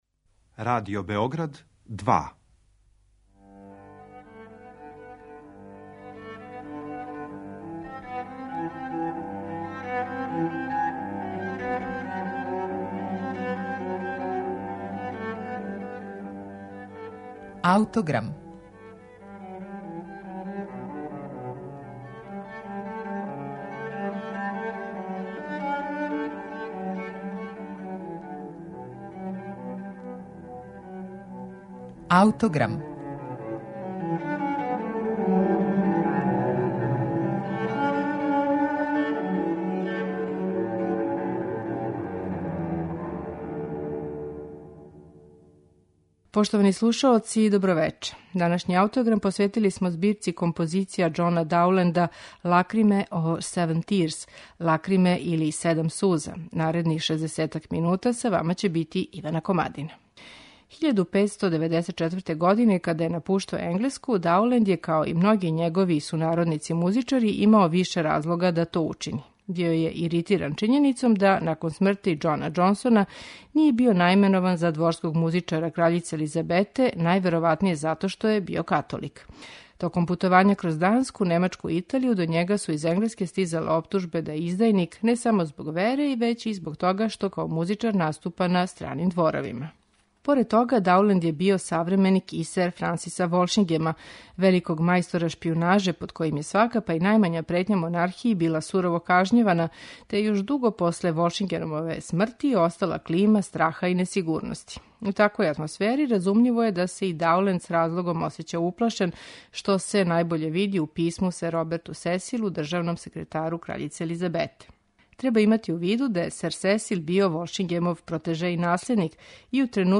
Збирку композиција за ансамбл виола и лауту "Лакриме или седам суза" Џон Дауленд је штампао 1603. године и посветио је краљици Ани, супрузи новог енглеског краља Џејмса Првог. Чини је 21 плесни комад, а окосницу представља низ од седам павана вема спорог темпа, које се смењују са паванама, гаљардама и падованама другачијег карактера. Седам спорих павана тематски су повезане заједничким музичким мотивом, такозваном "сузом која пада".